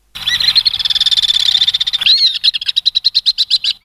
Martinet à ventre blanc, apus melba